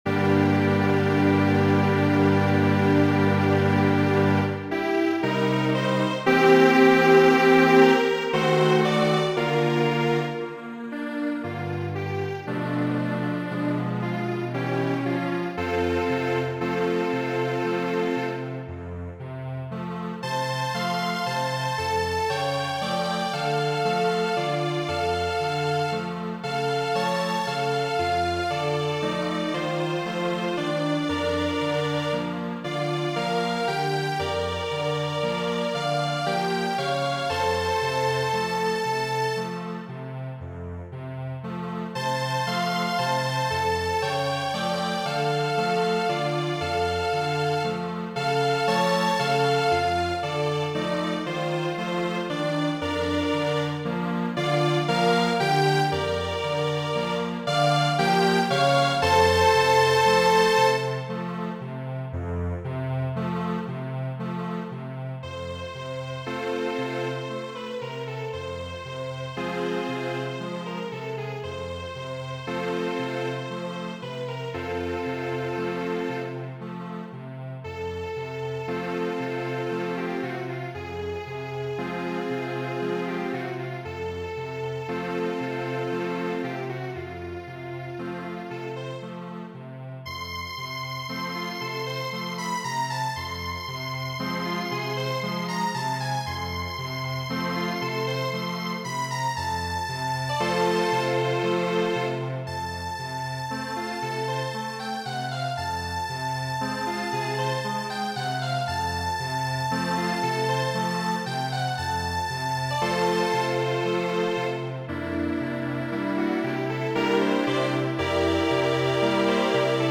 kaj tiun surdulan konversacion interrompas la ĥoro